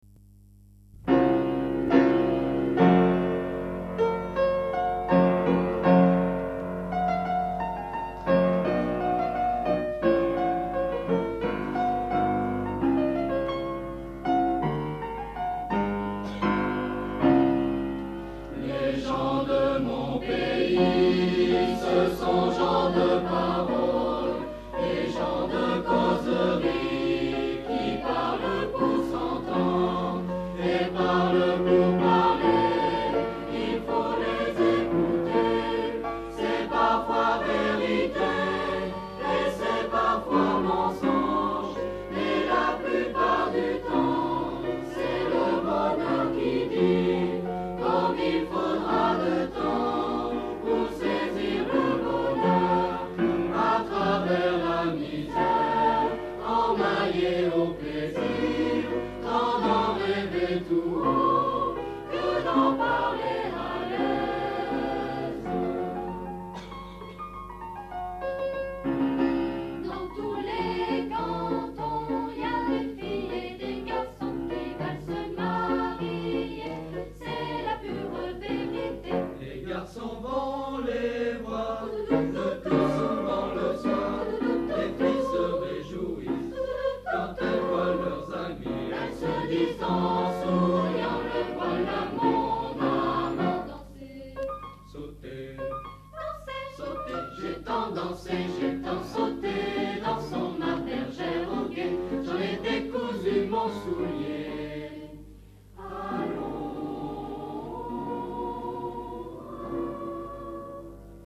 Rassemblement Cantilenes A Coeur Joie Samedi  10 mars 1986 à l'INSA de LYON
Extraits du concert final des Cantilènes A Coeur Joie de la Region Rhones-Alpes à l'INSA de Lyon Tous(T)  = Le Tempo Lyonnais(L) +